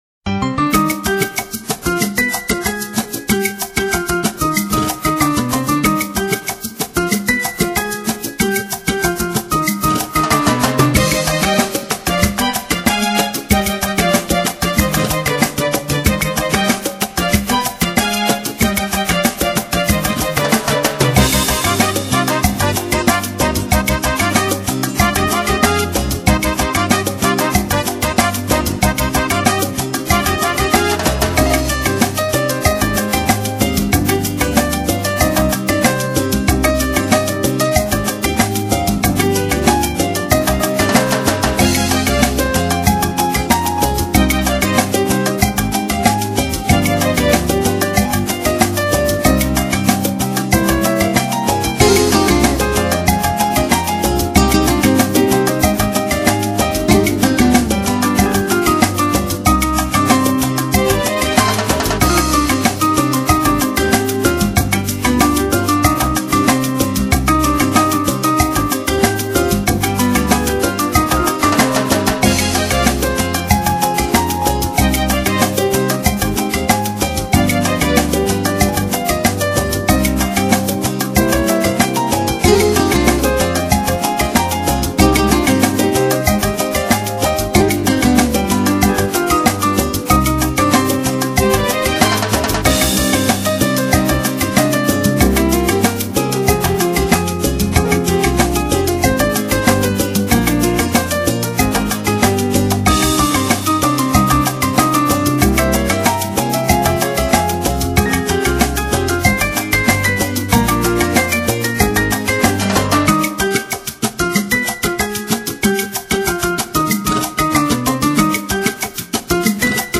流　　派： New Age